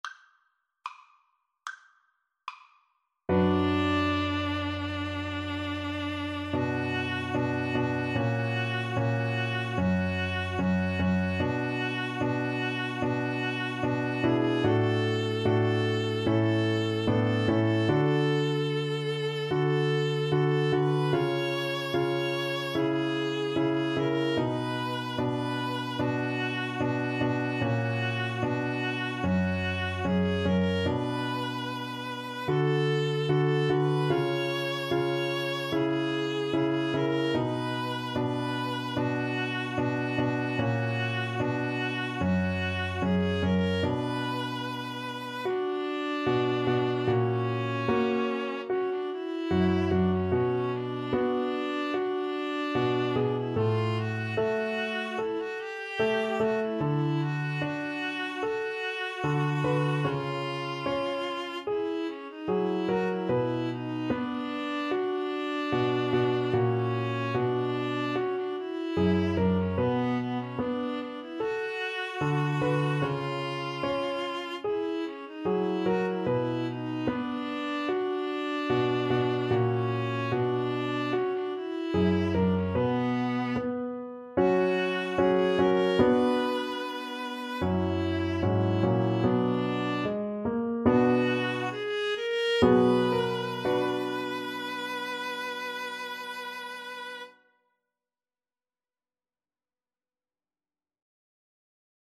Allegretto = c. 74
2/4 (View more 2/4 Music)
Viola Duet  (View more Easy Viola Duet Music)
Classical (View more Classical Viola Duet Music)